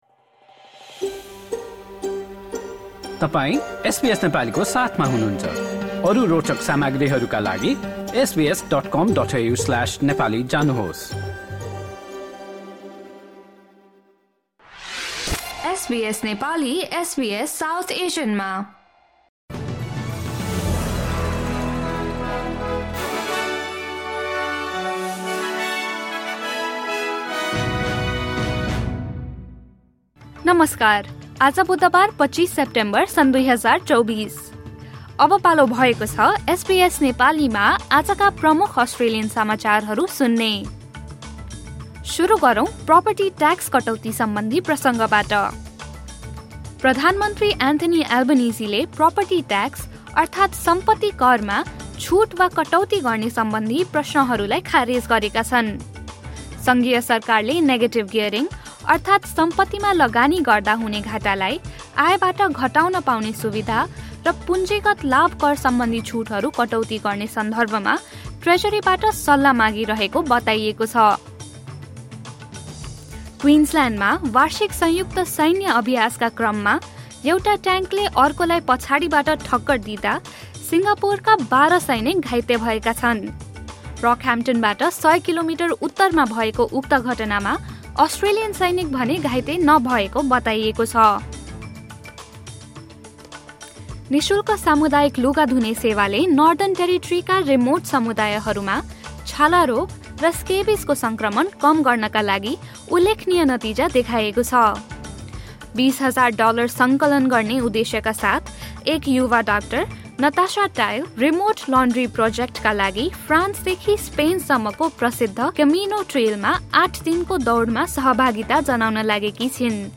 SBS Nepali Australian News Headlines: Wednesday, 25 September 2024